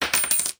getGold3.mp3